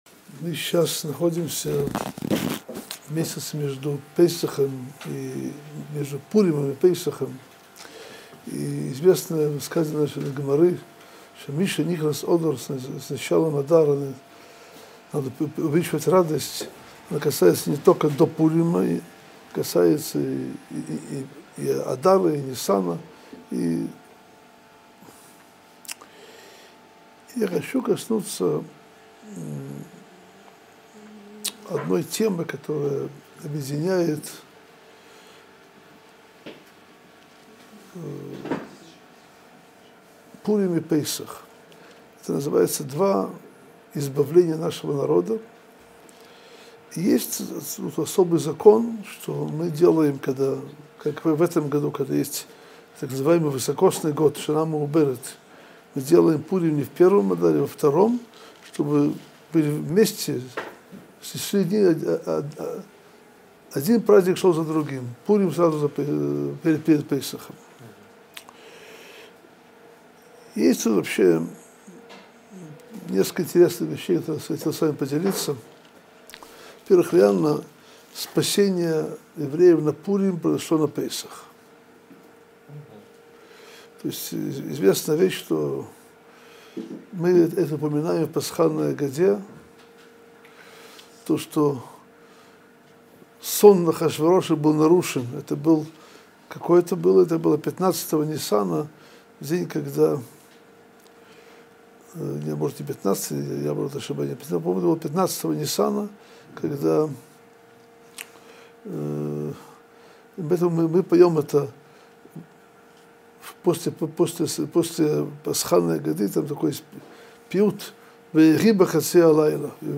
Содержание урока: Пуримское избавление пришло к евреям в дни Песаха.